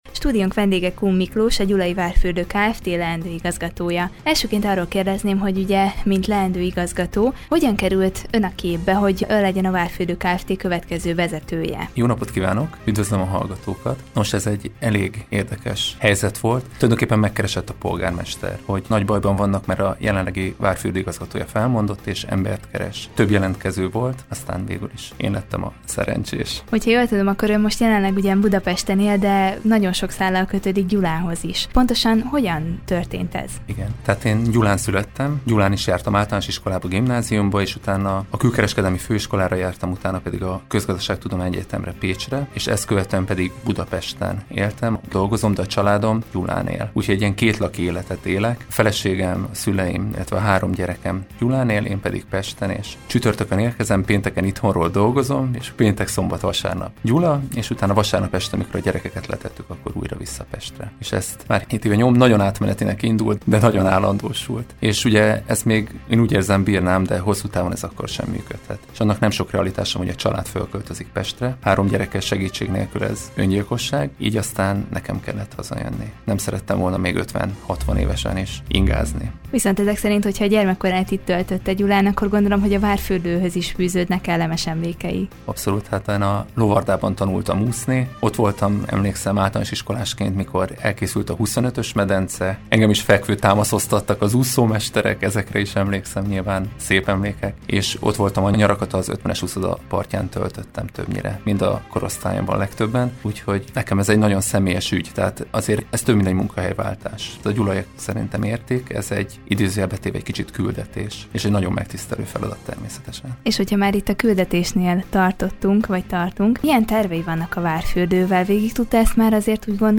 Vele beszélgetett tudósítónk a felkérésről valamint a fürdő előtt álló fejlesztésekről és változásokról.